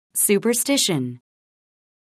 미국[sùːpərstíʃən]